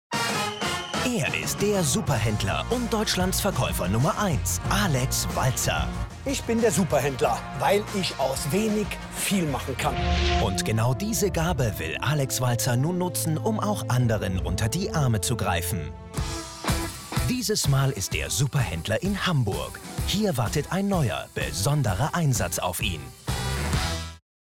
Erfahrener deutscher Sprecher.
Meine Stimme kann warm, beruhigend, bestimmt, dynamisch oder energetisch klingen, so wie Sie es brauchen.
Sprechprobe: Sonstiges (Muttersprache):
My voice can sound warm, calming, determined, dynamic or energetic as you need it to be.